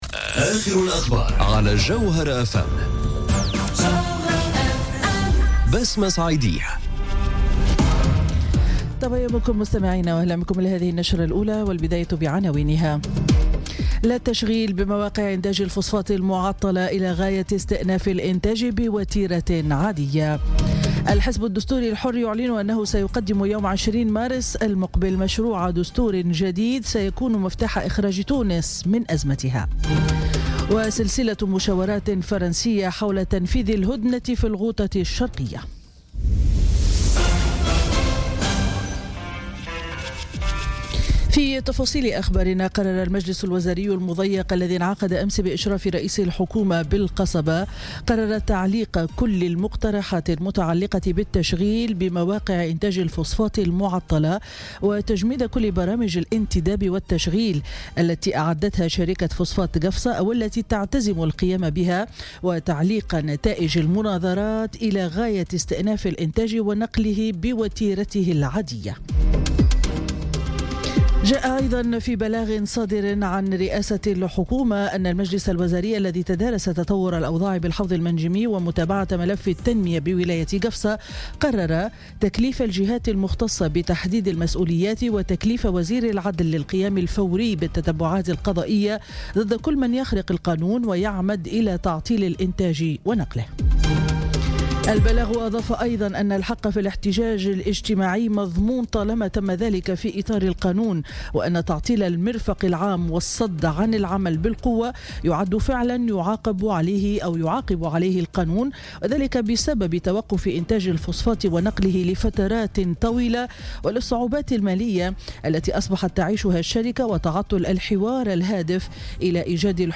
نشرة أخبار السابعة صباحا ليوم الأحد 4 مارس 2018